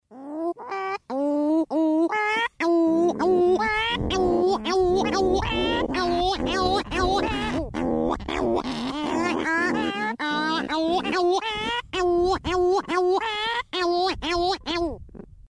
cagarro6.mp3